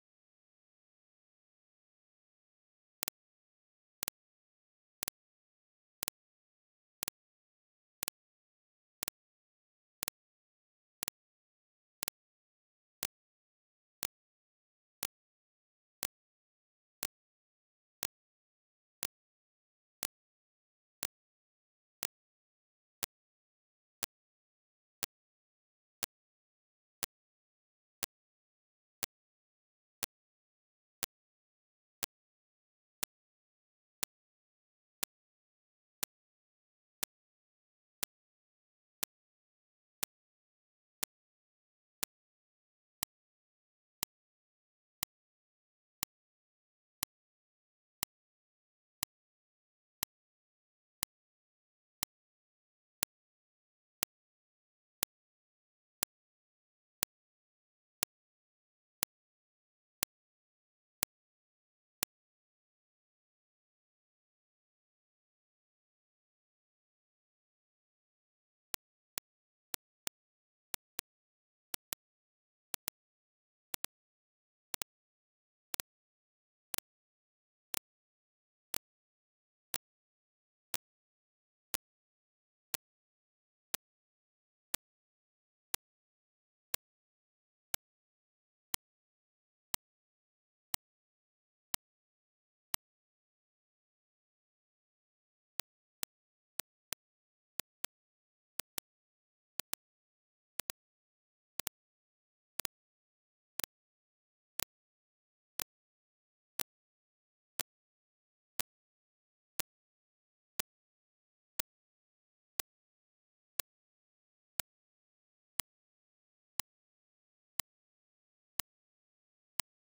Haas-Effekt.ogg